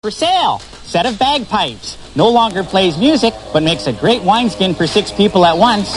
BagPipes.mp3